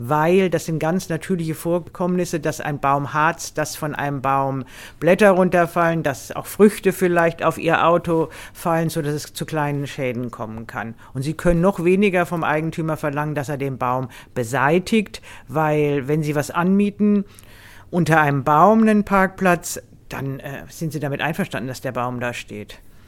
O-Ton: Vermieter eines Stellplatzes nicht für tropfendes Harz verantwortlich